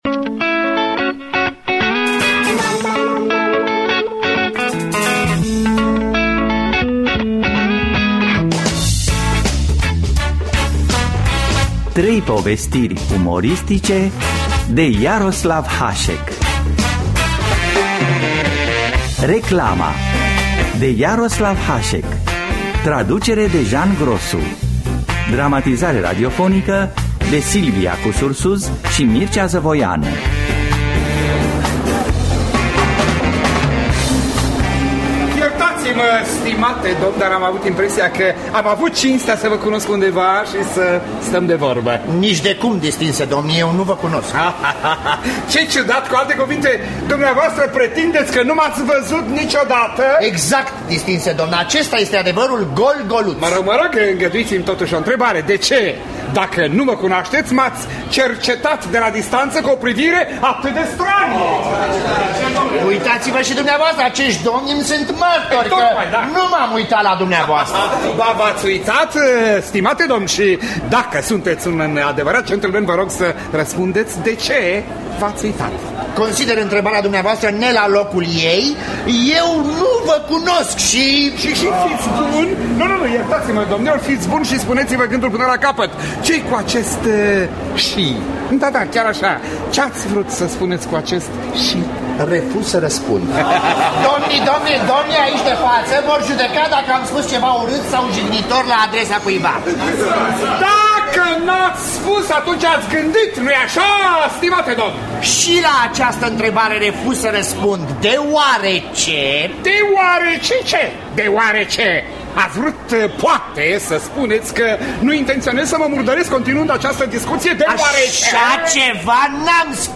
Trei povestiri umoristice: Reclama, Întâmplări din Dacice, Necazurile unui reporter de ştiri de Jaroslav Hasek – Teatru Radiofonic Online